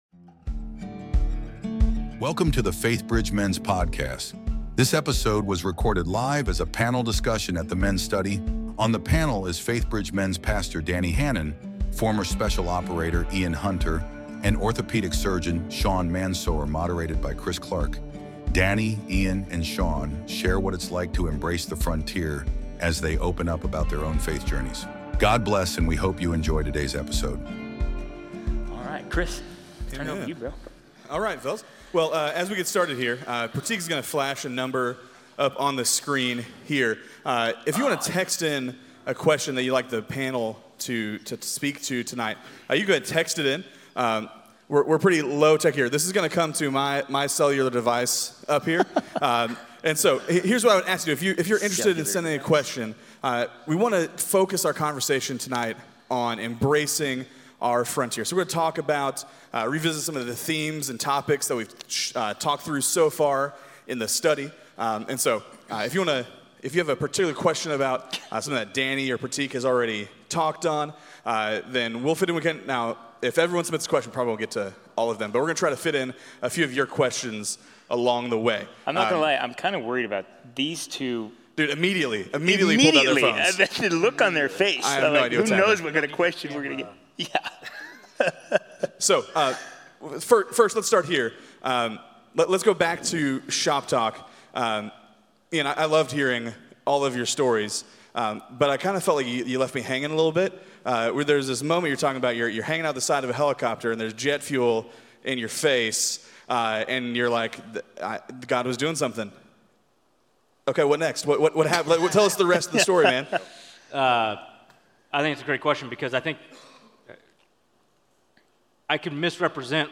Panel Discussion: The Frontiersmen - God's Covenants Study | Week 4